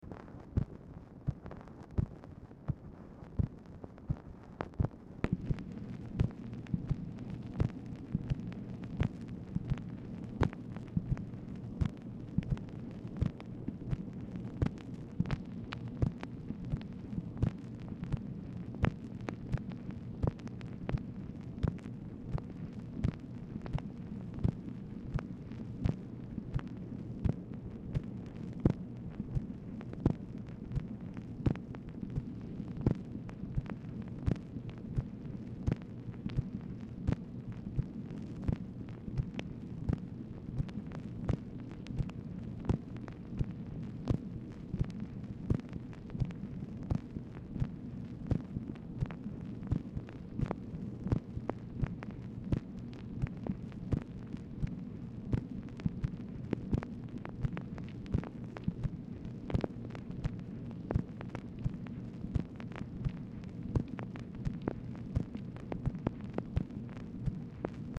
Telephone conversation # 5961, sound recording, MACHINE NOISE, 10/24/1964, time unknown | Discover LBJ
Dictation belt